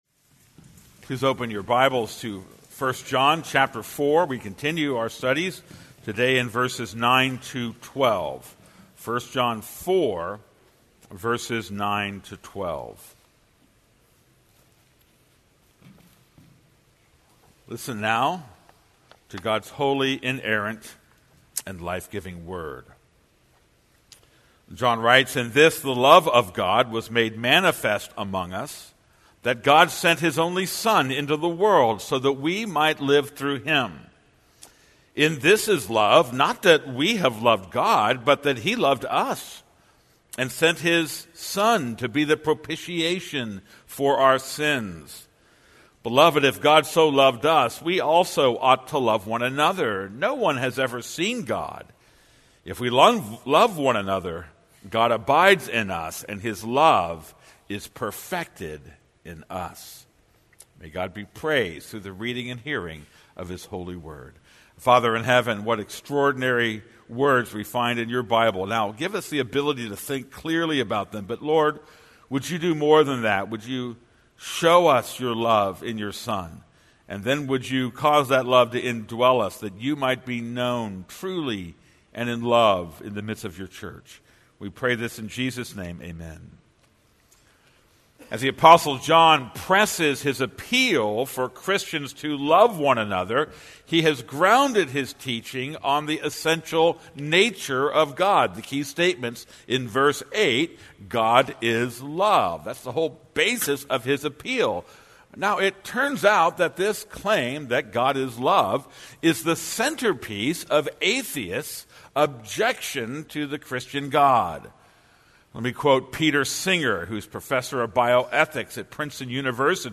This is a sermon on 1 John 4:9-12.